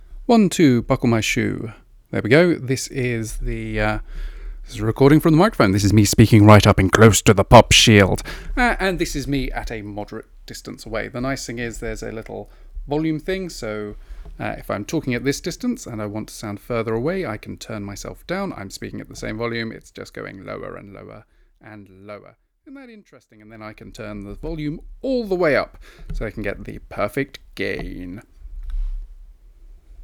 No noticeable hiss. Captured my voice perfectly. It picked up a little clack from my keyboard as I typed.
mictest.mp3